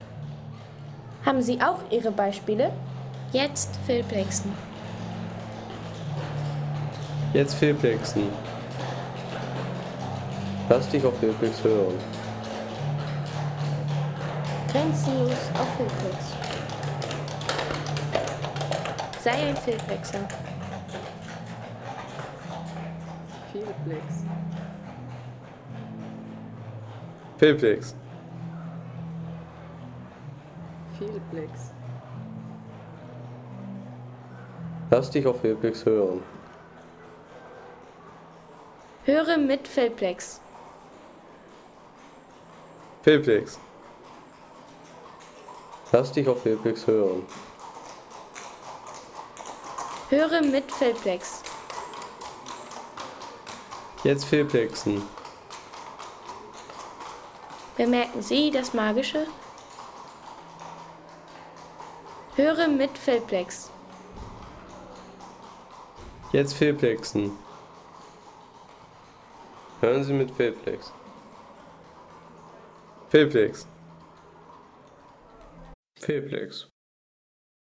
Galoppieren der Zweispänner
Galoppieren der Zweispänner durch das Dresdner Georgentor.